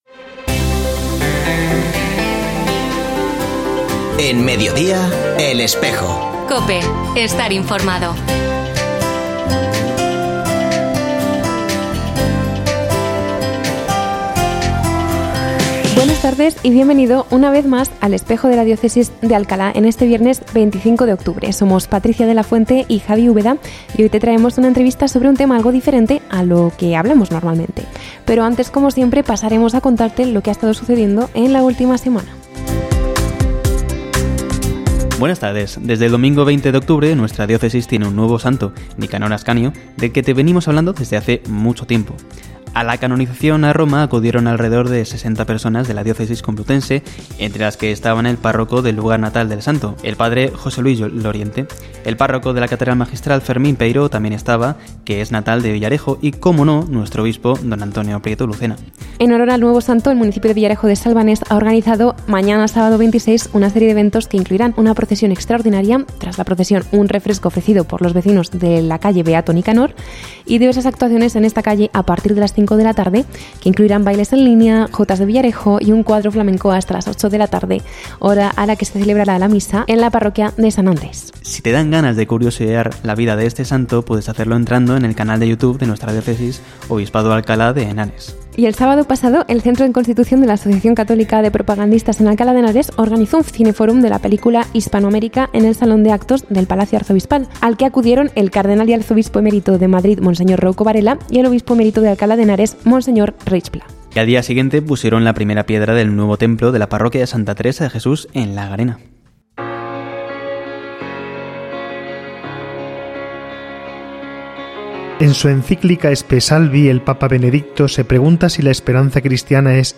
Escucha otras entrevistas de El Espejo de la Diócesis de Alcalá
Se ha vuelto a emitir hoy, 25 de octubre de 2024, en radio COPE. Este espacio de información religiosa de nuestra diócesis puede escucharse en la frecuencia 92.0 FM, todos los viernes de 13.33 a 14 horas.